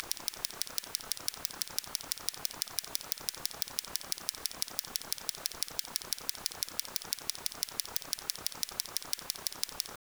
Klickgeräusch Dummy (Attachment1_zaud000013.wav, audio/x-wav, 1.7 MBytes)